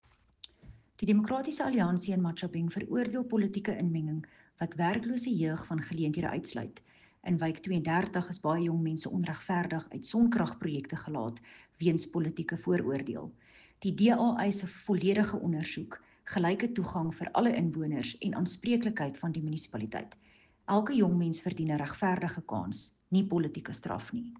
Afrikaans soundbites by Cllr René Steyn and